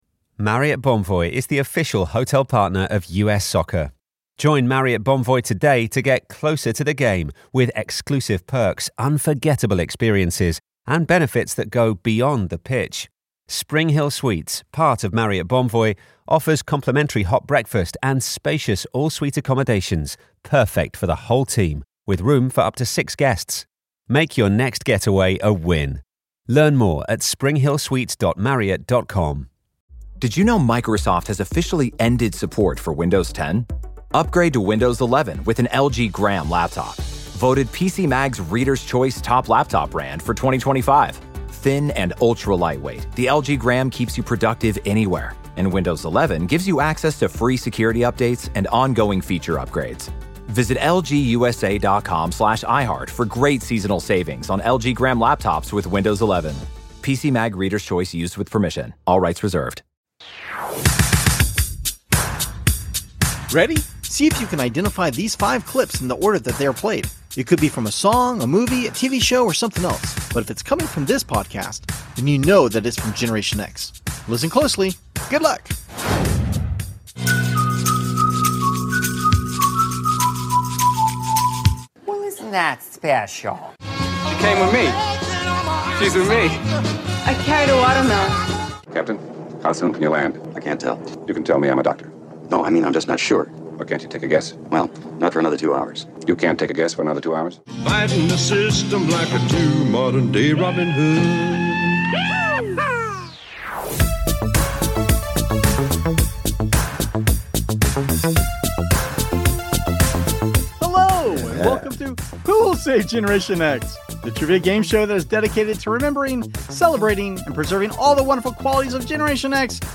We welcome two first time guests who are both long time supporters of the show, to bring plenty of laughs as we play games and reminisce about our pop culture during Generation X. We hope it's worth the wait and an episode that you'll especially l...